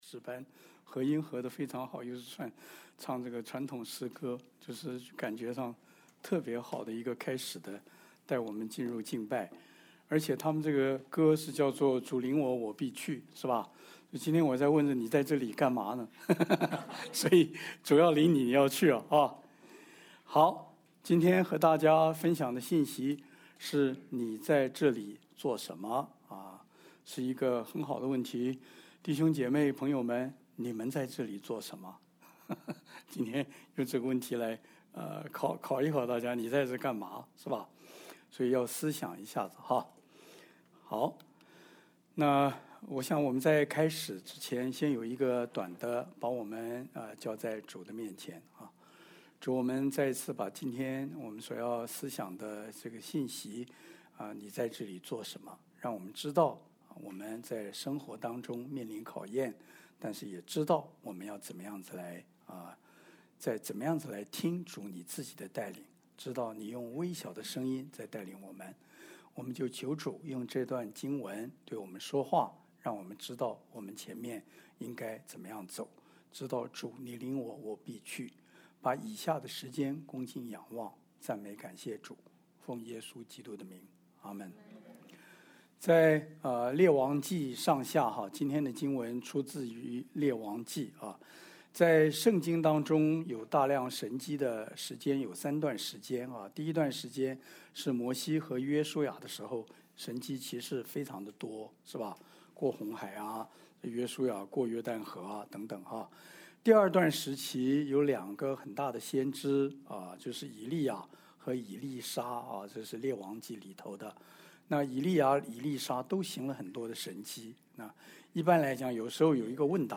1 Kings 19:9–13 Service Type: 主日证道 Download Files Notes « 天国的奥秘 你去照樣行吧！